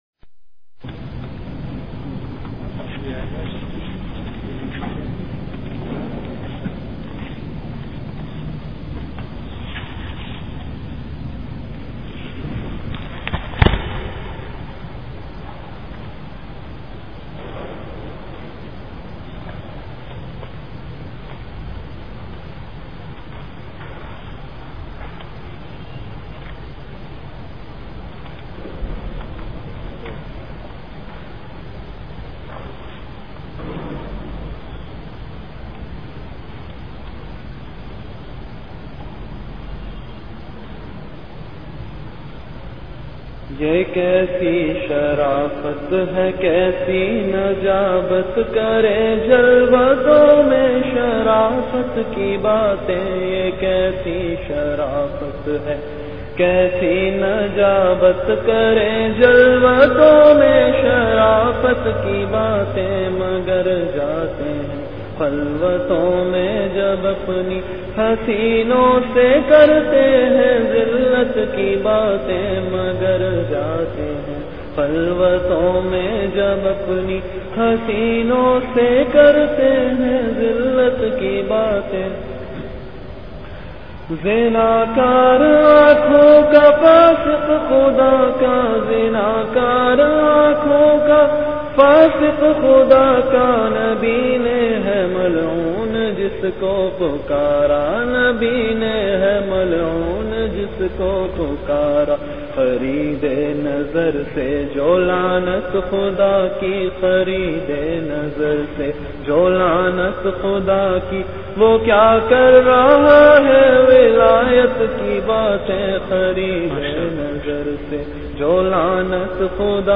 Delivered at Home.
Category Bayanat